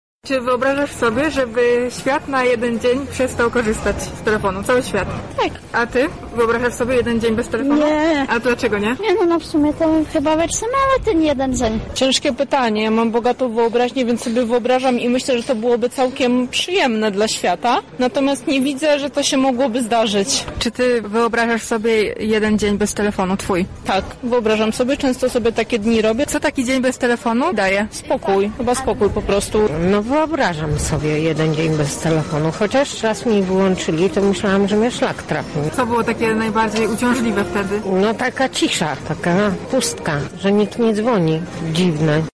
Czy w dzisiejszych czasach możliwe jest nieużywanie smartfona choćby przez jeden dzień? Zapytaliśmy o to napotkanych mieszkańców Lublina:
Sonda